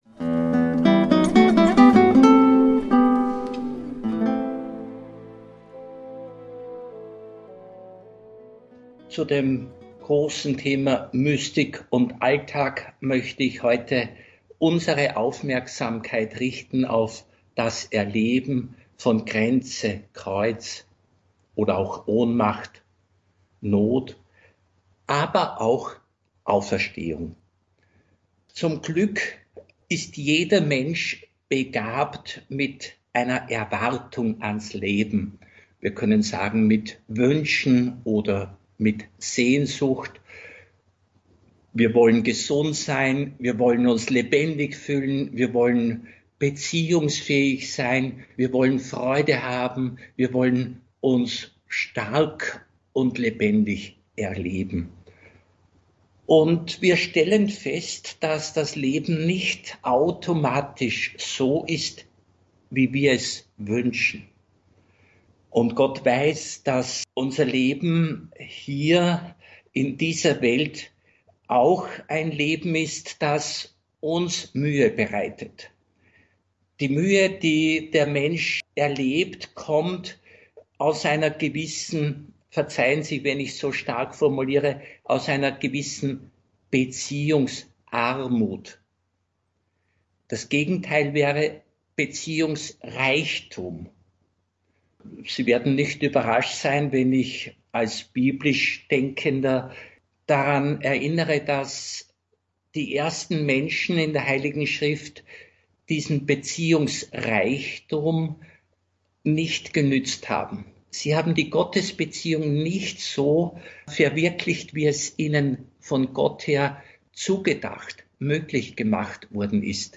(Radio Maria Aufzeichnung vom 25.3.2025) Mehr